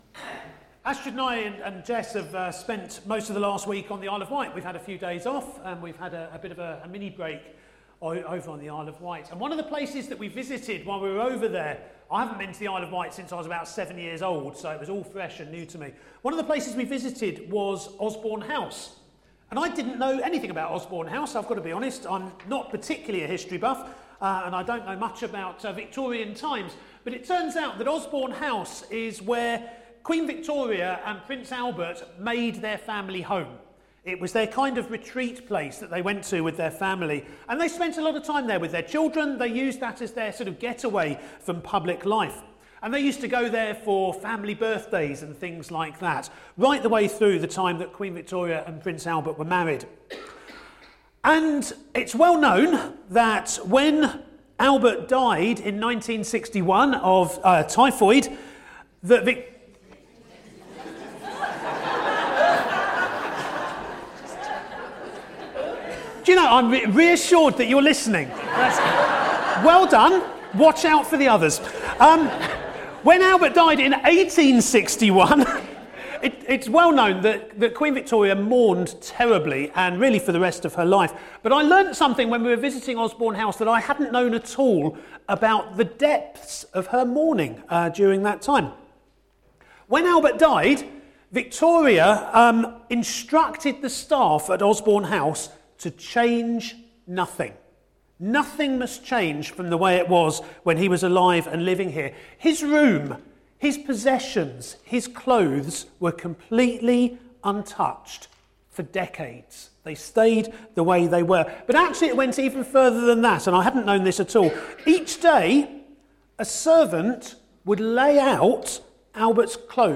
A message from the series "God: in Person."